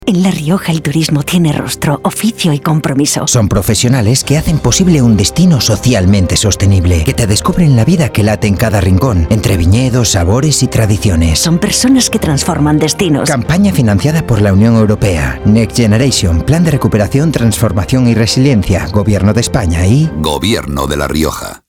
Cuñas radiofónicas